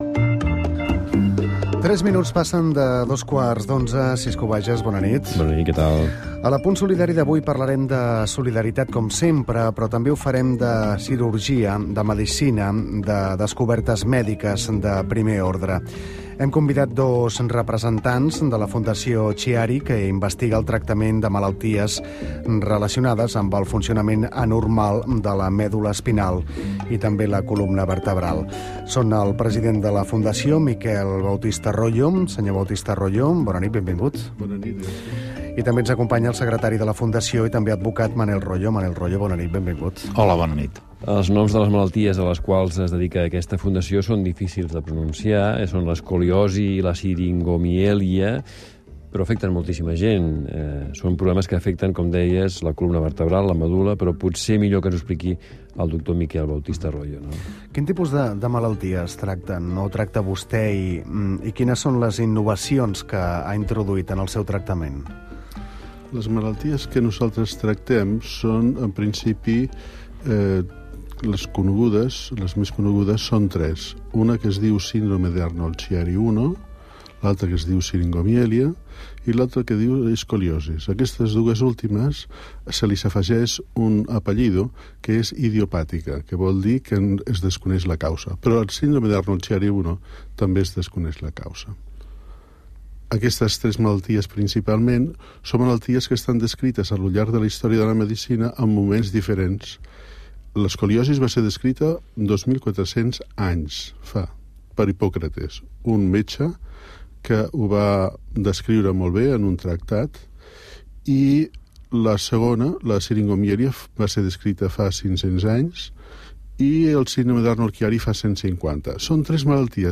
ICSEB 医疗团队在 COMRàdio 节目“Tots x Tots”接受采访，分享脊髓及脊柱相关疾病的诊疗经验、临床案例与专业见解，提供深入医疗资讯。